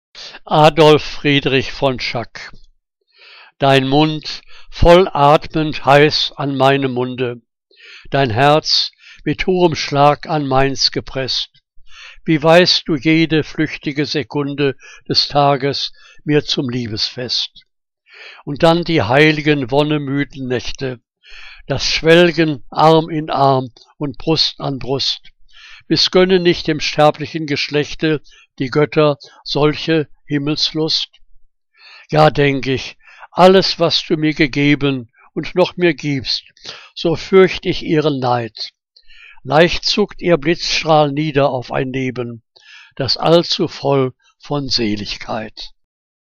Liebeslyrik deutscher Dichter und Dichterinnen - gesprochen (Adolf Friedrich von Schack)